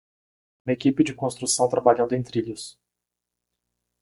Read more Noun Verb equipar to equip or outfit to rig Read more Frequency B2 Pronounced as (IPA) /eˈki.pi/ Etymology Borrowed from French équipe In summary Borrowed from French équipe (“team”), from équiper (“to equip”).